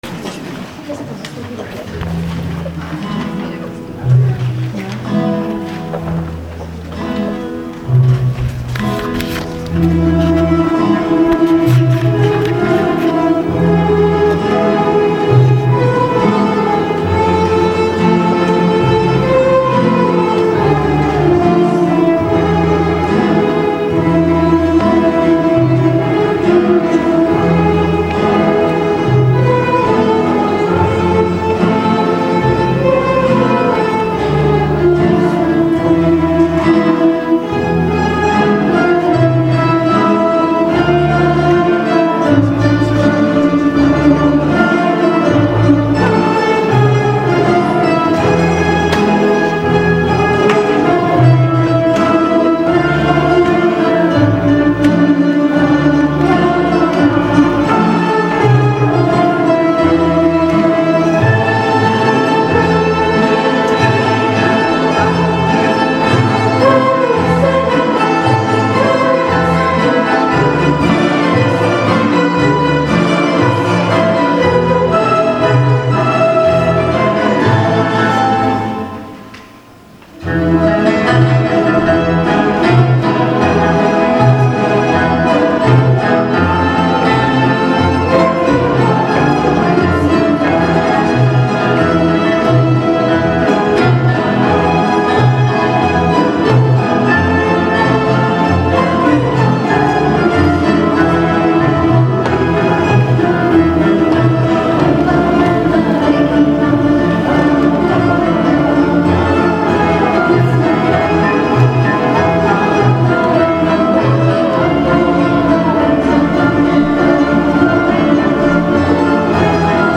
Pěvecké sdružení Collegium Bonum